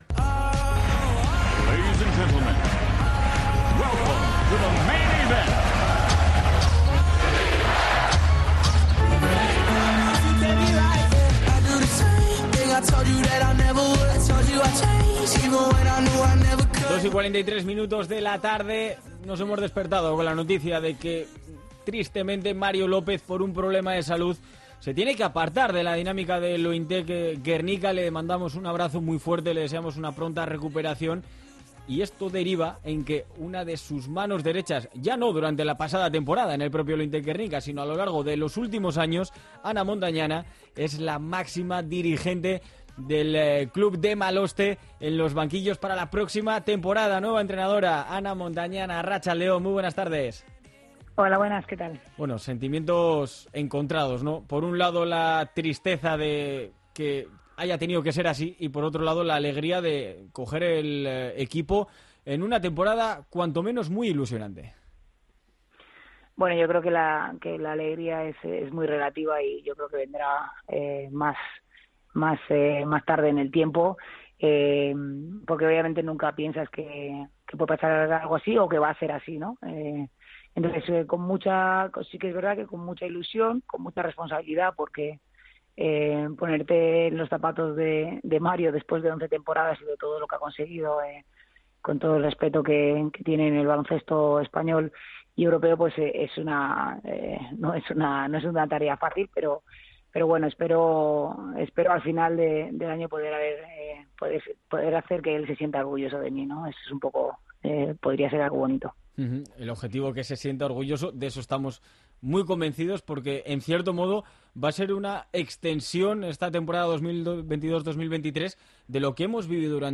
Entrevistas deportivas